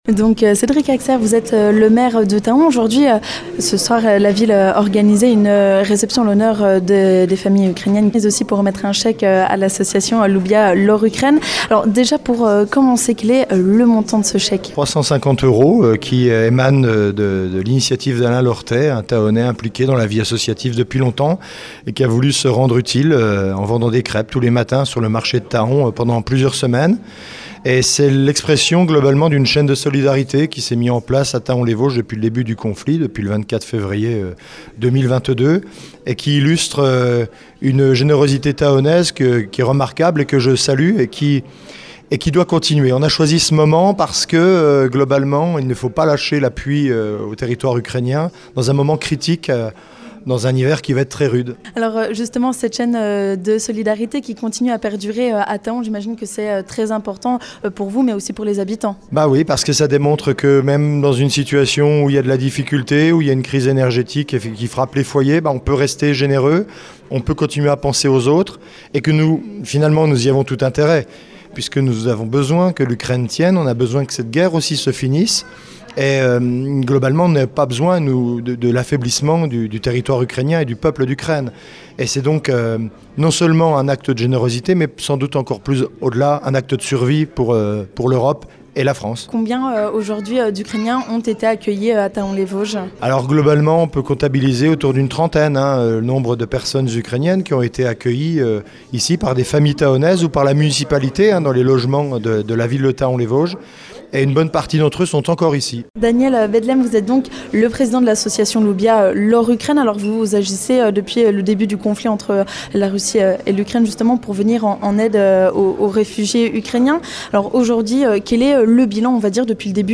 %%La rédaction de Vosges FM vous propose l'ensemble de ces reportages dans les Vosges%%